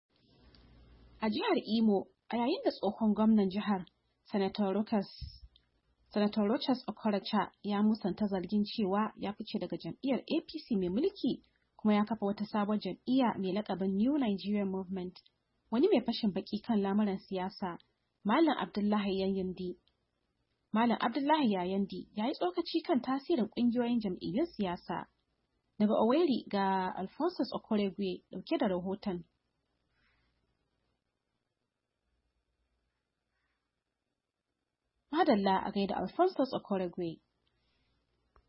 Saurari cikkaken rahaton